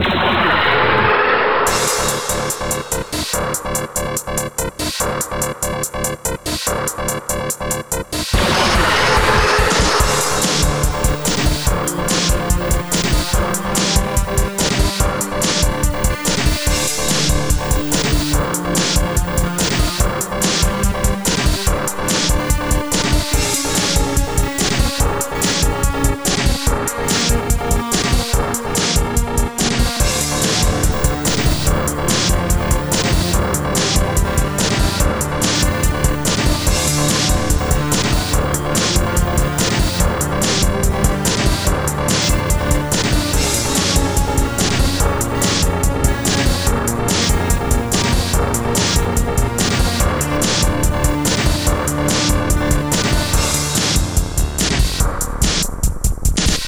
and a "brutal" theme for fast, hard fighting situations